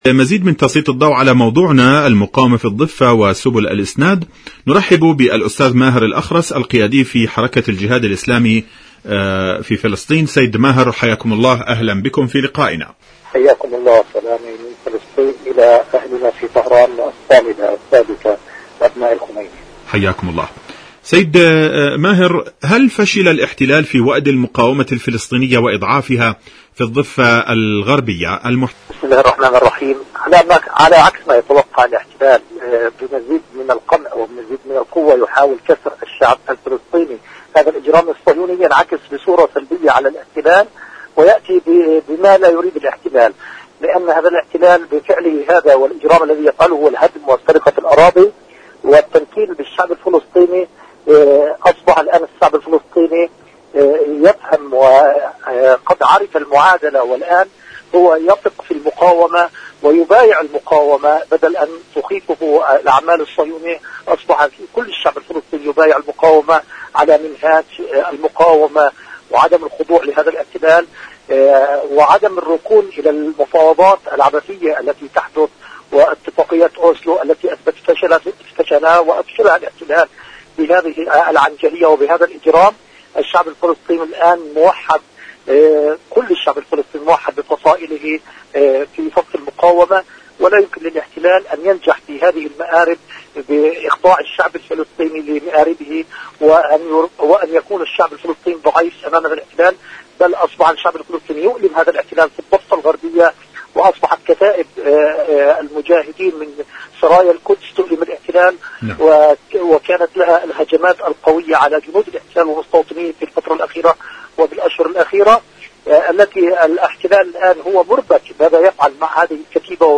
المقاومة في الضفة وسبل الإسناد.. مقابلة